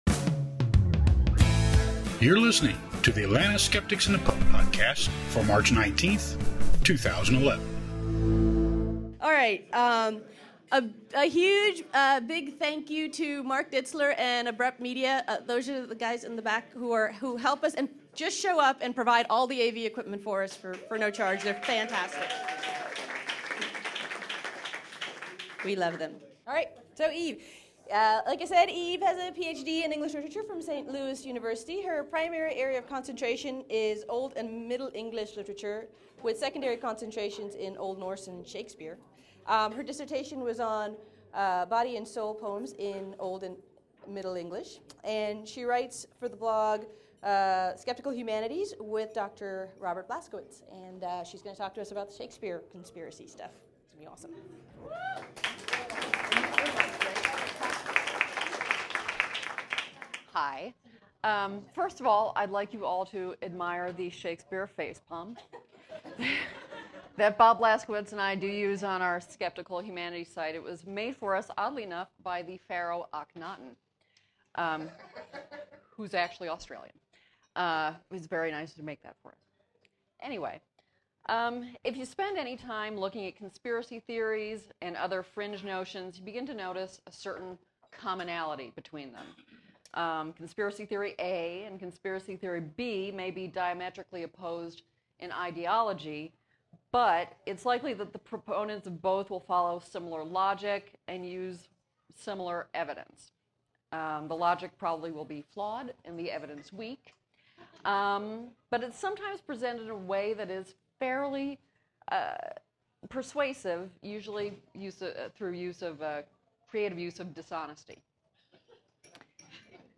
In March I gave a presentation to the Atlanta Skeptics about the Shakespeare authorship question and compared the flawed reasoning of Shakespeare conspiracy theorists to more modern conspiracy theories.